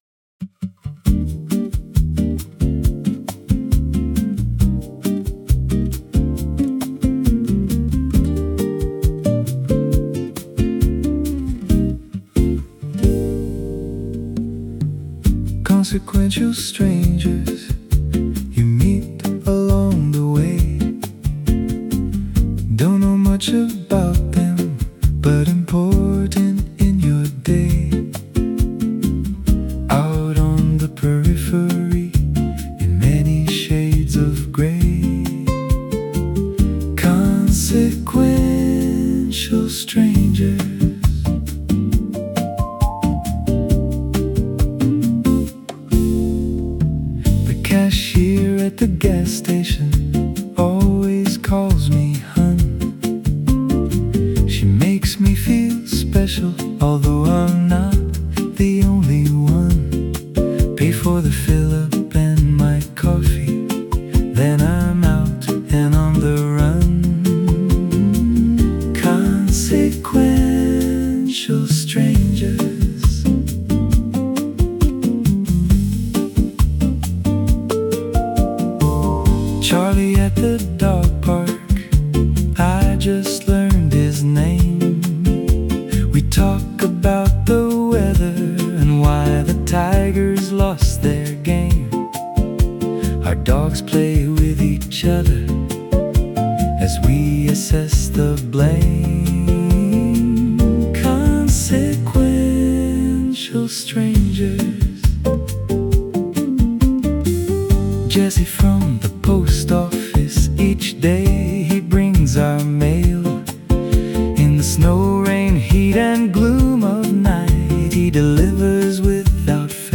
I originally wrote this as a reggae song, but decided to go for more of a Bossa Nova feel. I would have done more with personally, but I’m in a hotel room in Marquette MI getting some other things accomplished, so I just played uke and sang the song into my Audigo and uploaded it to Suno with the appropriate prompt.